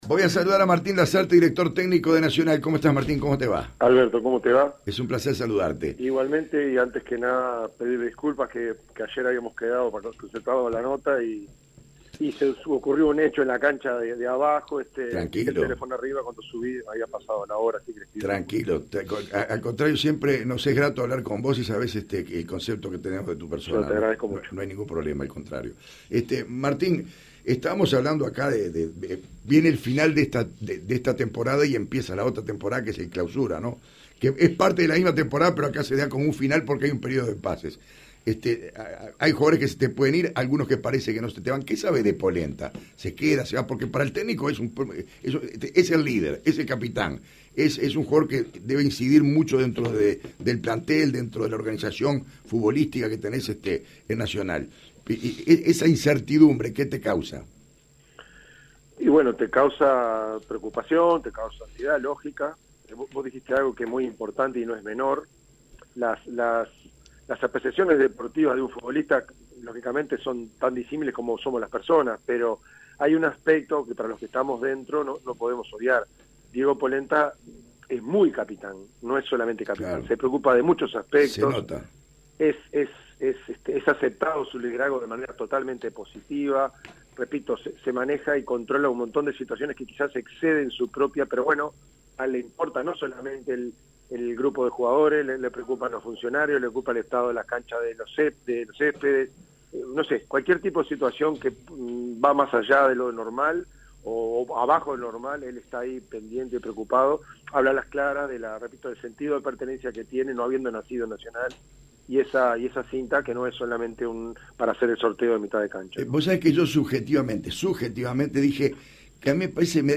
La Oral Deportiva charló con Martín Lasarte.